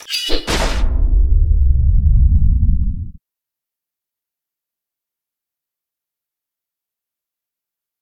swingsword.ogg.mp3